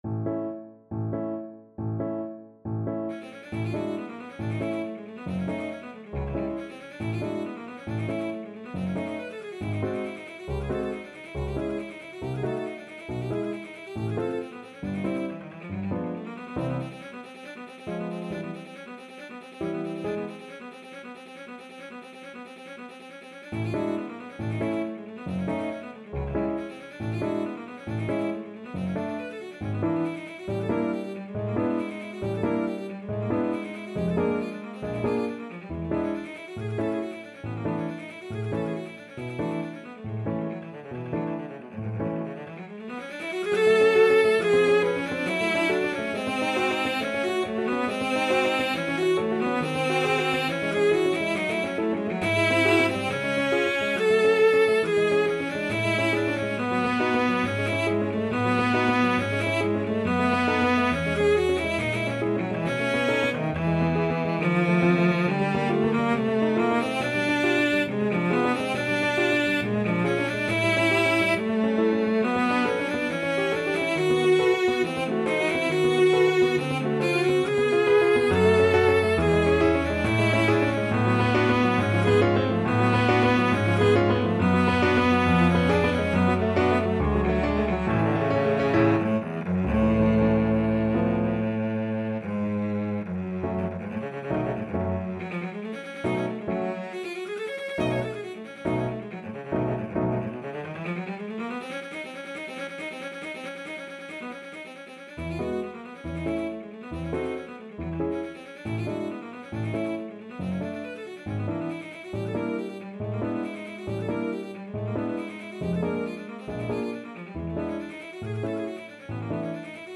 Free Sheet music for Cello
Cello
A major (Sounding Pitch) (View more A major Music for Cello )
2/4 (View more 2/4 Music)
Allegro vivo =138 (View more music marked Allegro)
Classical (View more Classical Cello Music)